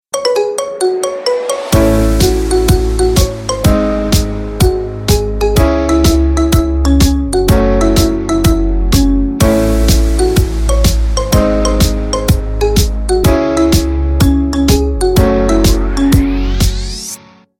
Рингтон